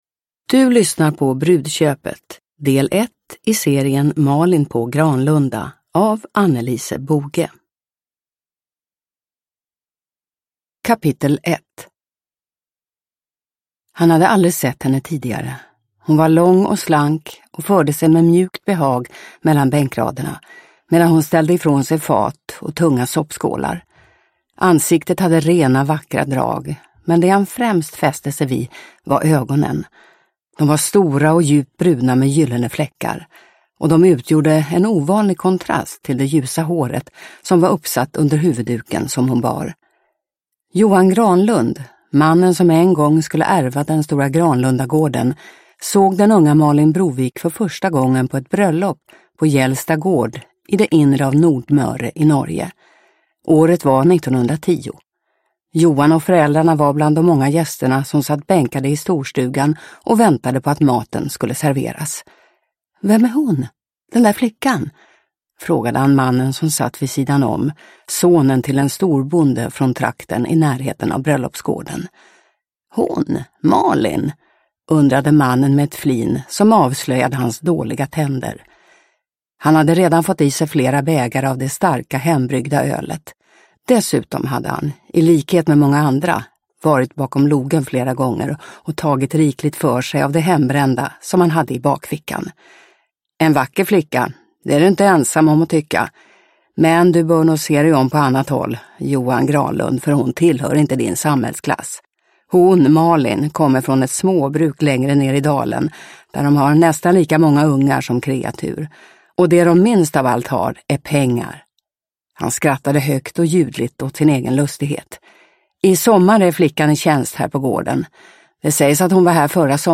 Brudköpet – Ljudbok – Laddas ner